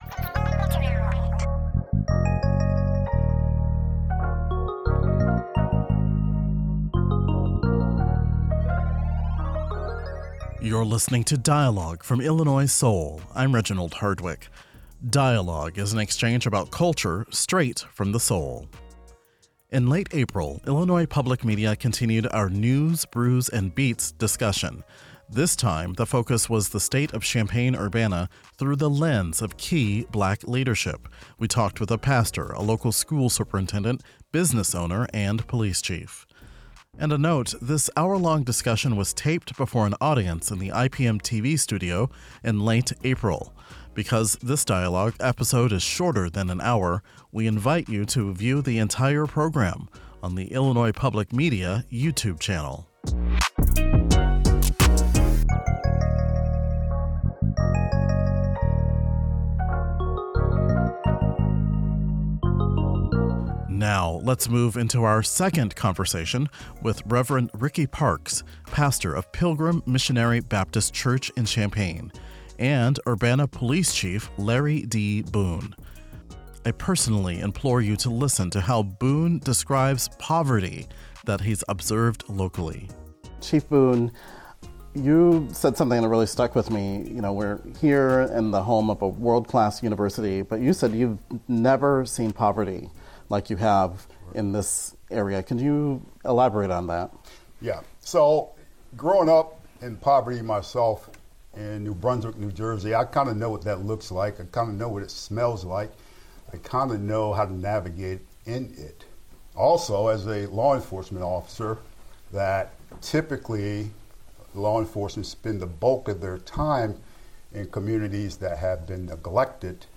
This segment of Dialogue continues by revisiting a discussion on the state of Champaign-Urbana's Black community through the lens of local Black leaders.